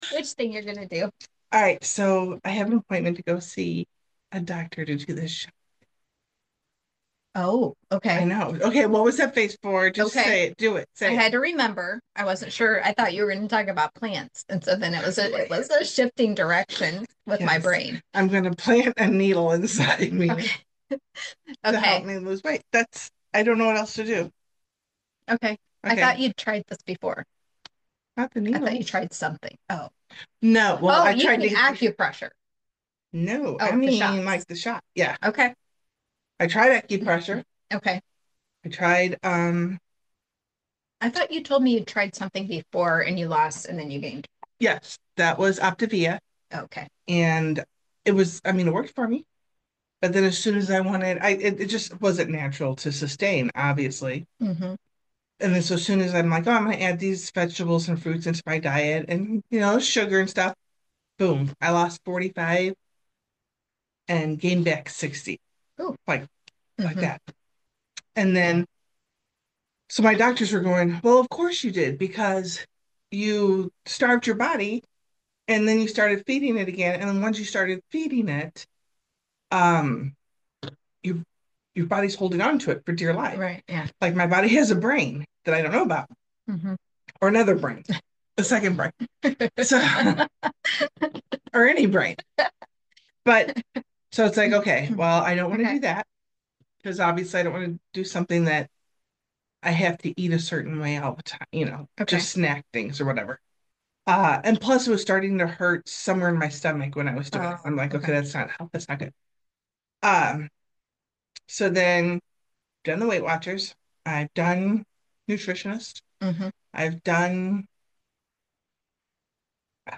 Pre-Show Banter: Weight Loss, Rest & Real Talk